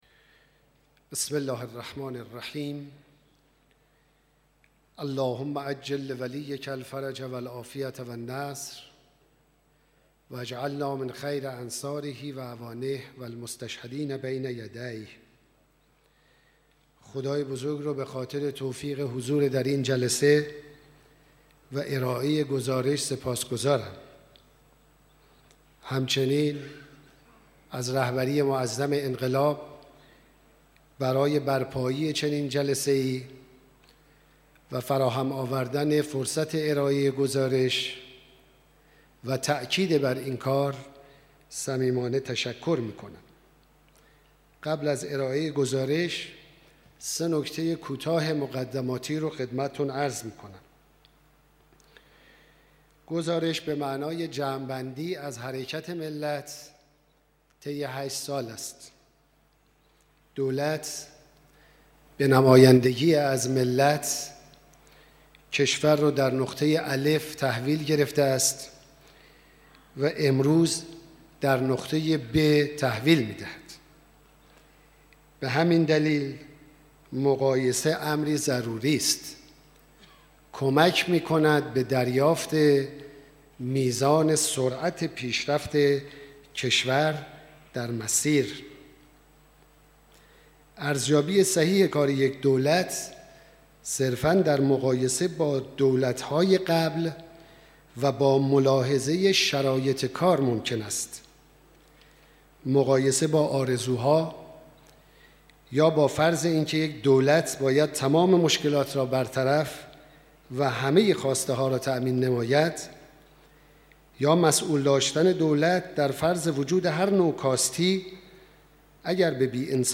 سخنان دکتر احمدی‌نژاد در دیدار مسئولان نظام